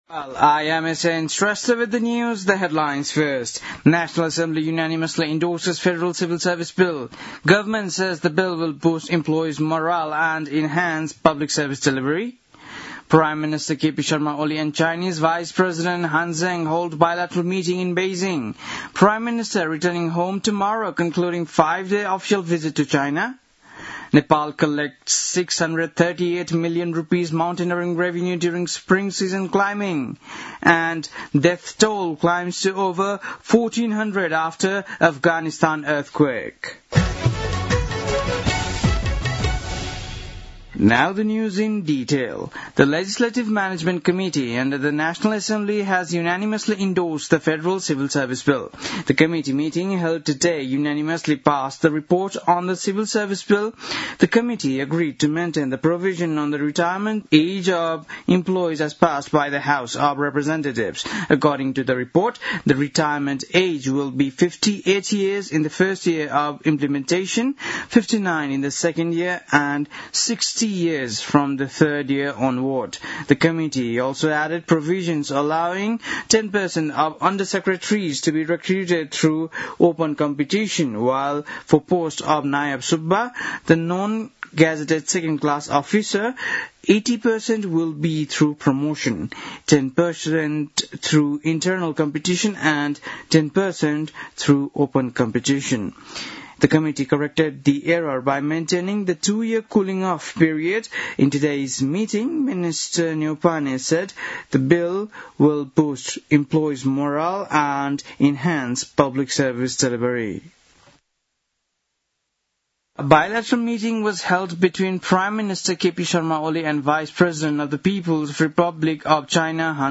बेलुकी ८ बजेको अङ्ग्रेजी समाचार : १७ भदौ , २०८२